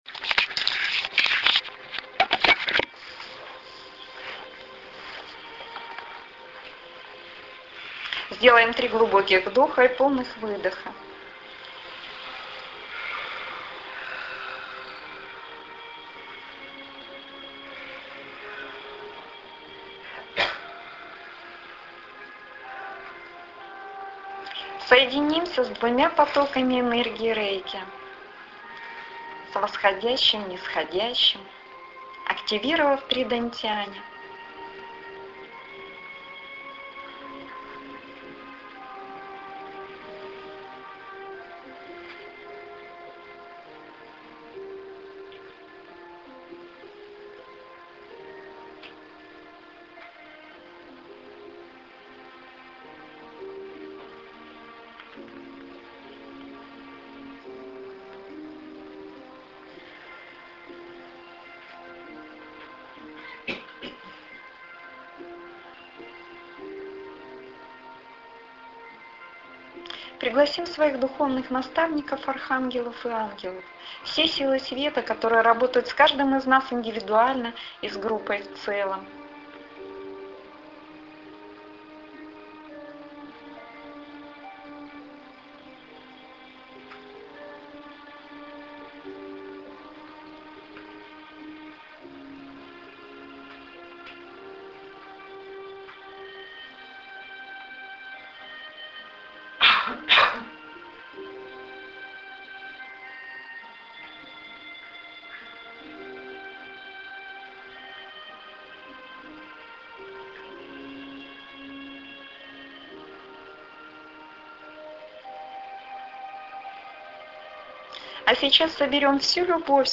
Спиральная очистка (аудио-медитация) Текст этой медитации был опубликован на страницах интернета, сложно сейчас вспомнить адрес.
Вот и сегодня 04.01.2011 года на очередном занятии мы провели эту медитацию.